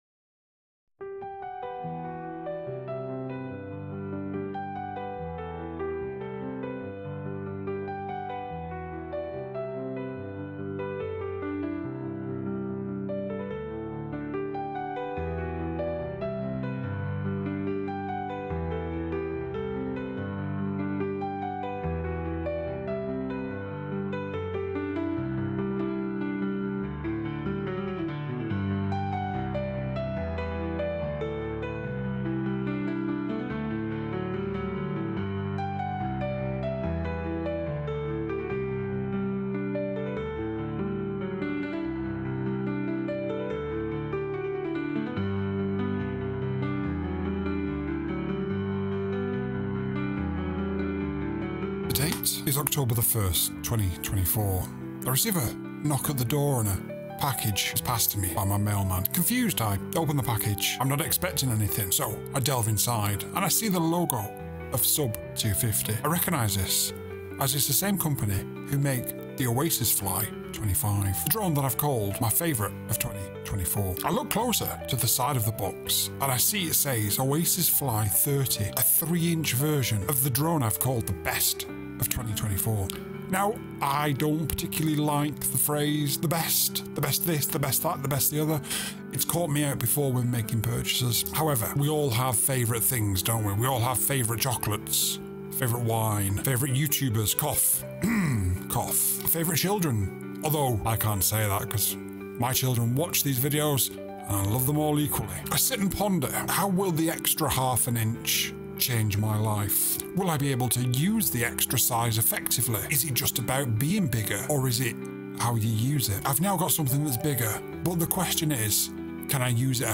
My voice over sounds bad, please help
I think the problem is totally in the balance between your piano track and your voice track. If you look at the wave form of the track you see that the piano sections are constant, but your voice track is much lower overall with a few significant peaks.
I downloaded the track and did a fair amount of rebalancing the volumes, and added a slight fade at each transition point, compressed the voice some to lower some of the bigger transients that will limit the overall volume.